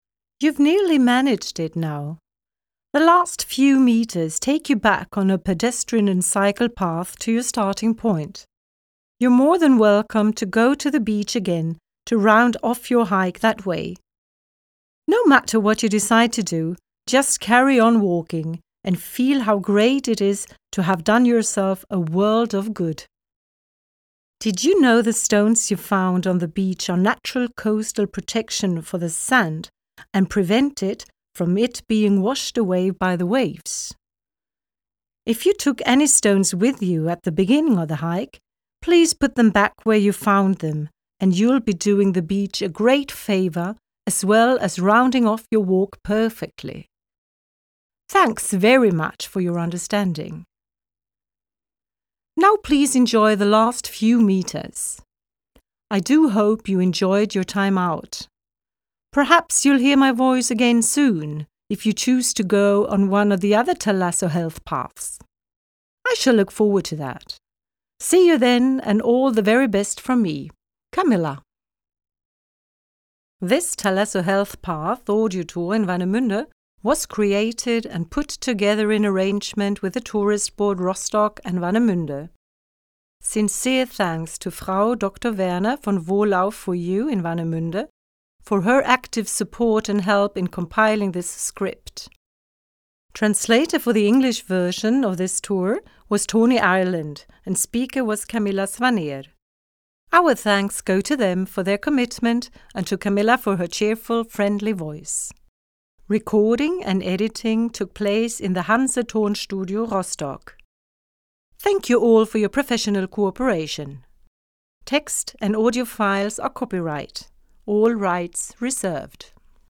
Audioguide
Enjoy your hike and the audio tour with easy exercises that you can do along the way!
Responsible for the sound recordings: Sound studio at Stralsund University of Applied Sciences.